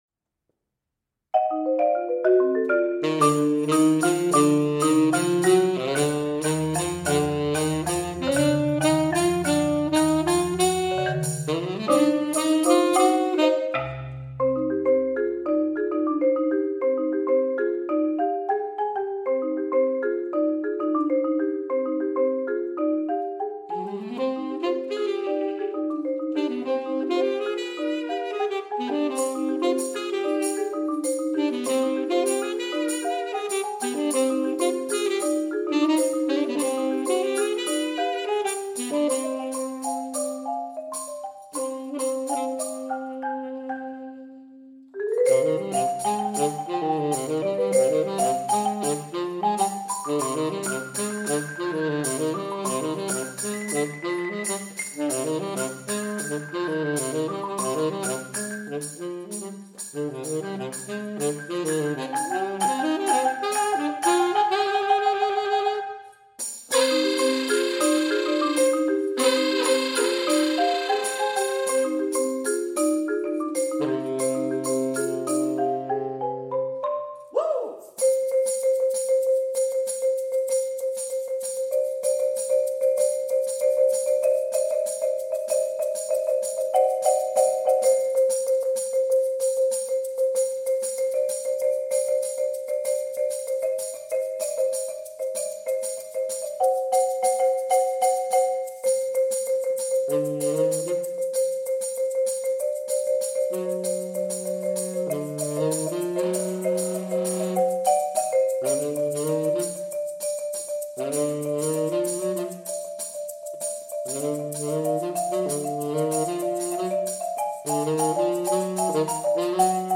Genre: Duet for Tenor Saxophone & Marimba
Tenor Saxophone
5-octave Marimba
(+ Pedal Tambourine & Egg Shaker)